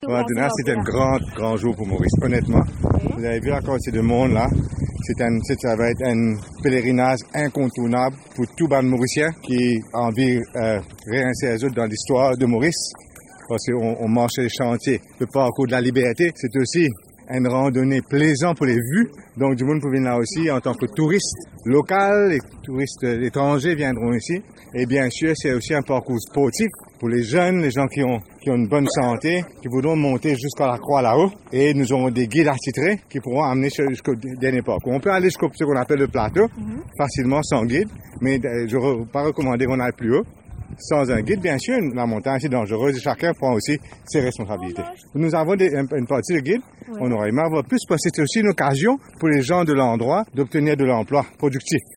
La voie d’accès menant jusqu’au sommet du Morne a été inaugurée, dimanche 24 juillet. Présent à l’occasion, le ministre du Tourisme Xavier-Luc Duval a déclaré que c’est un jour important pour Maurice.